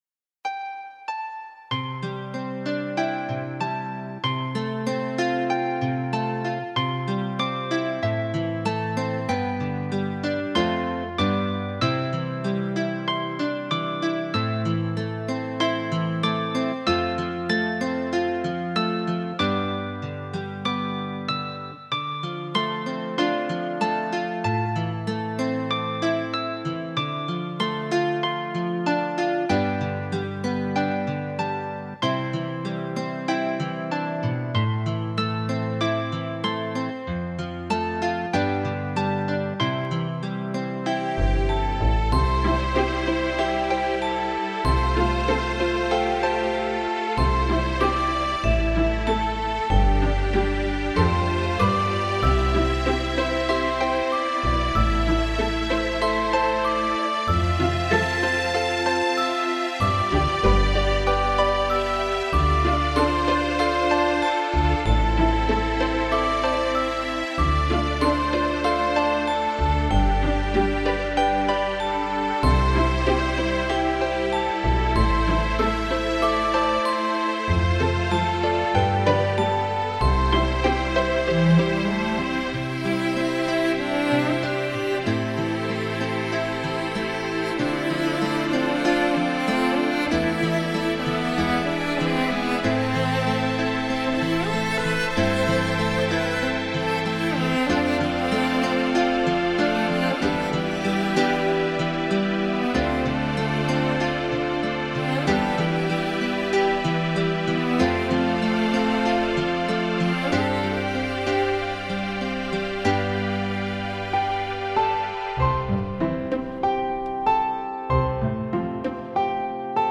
柔情款款的旋律與細 膩的演奏技巧相呼應